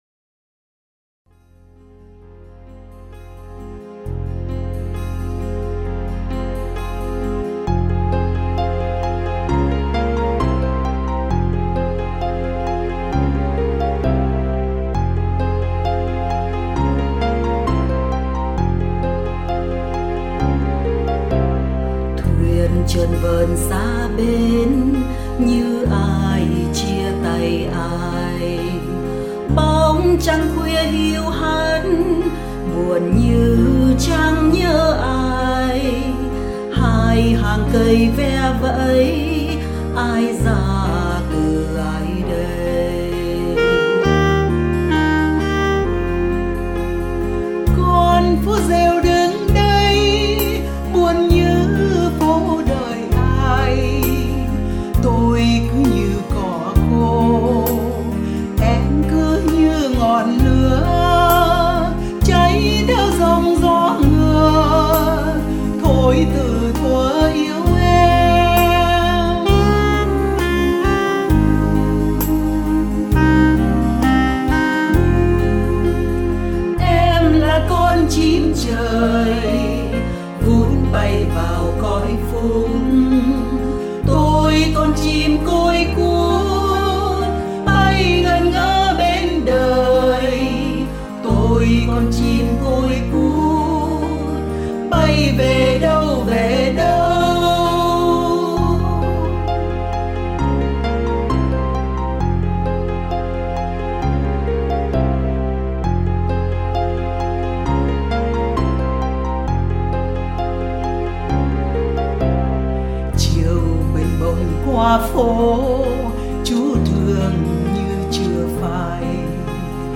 Tiếng hát